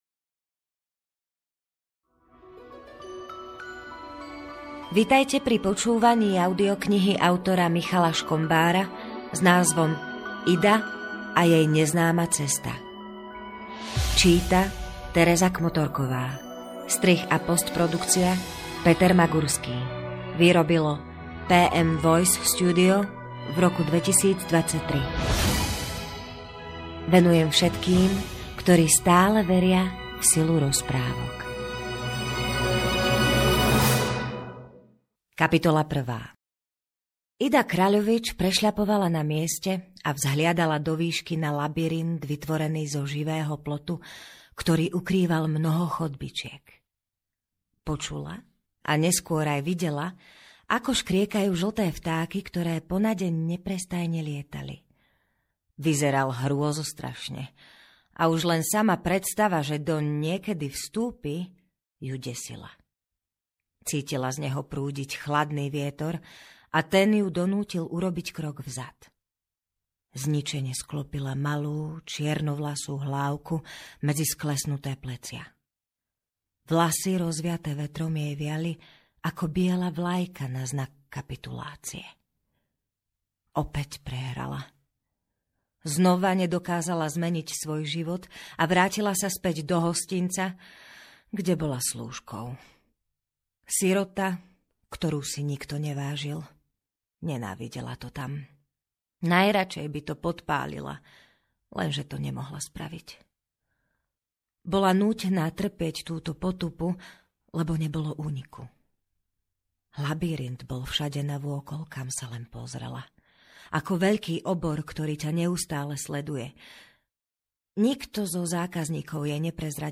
Ida a jej neznáma cesta audiokniha
Ukázka z knihy